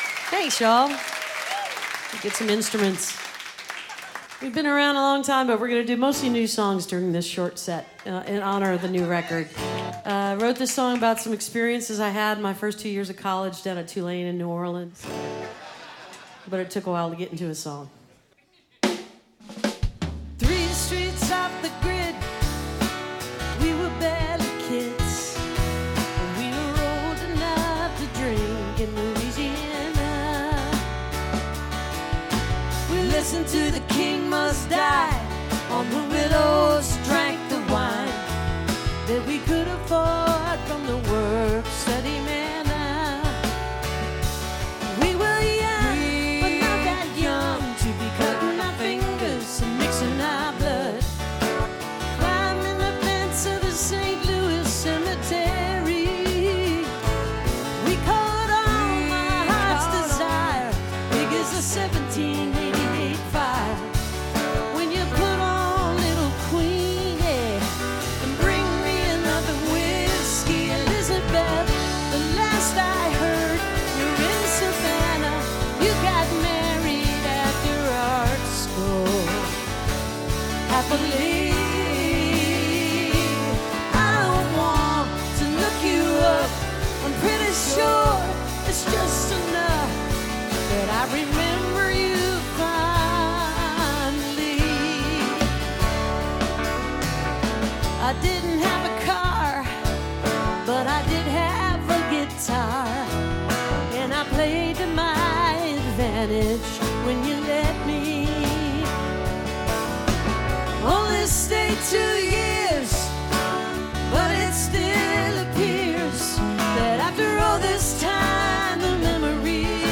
(recorded from the webcast)